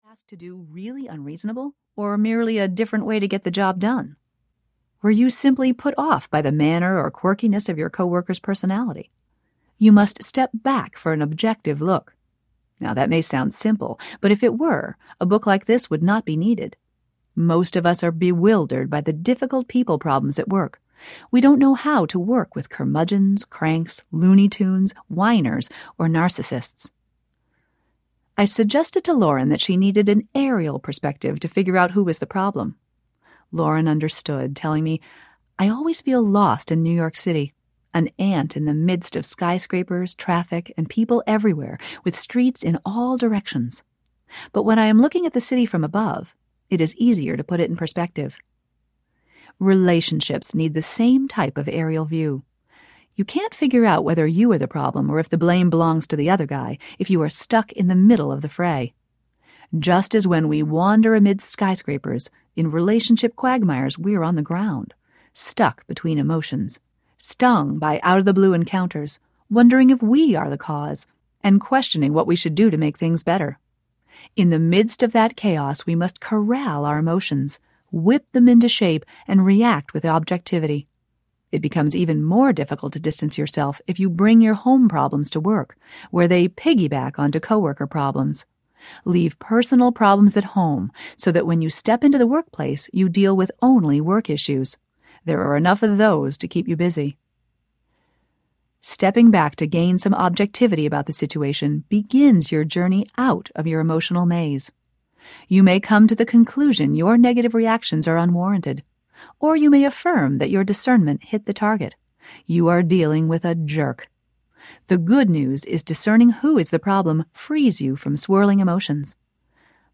Working Successfully with Screwed-Up People Audiobook
Narrator
6.25 Hrs. – Unabridged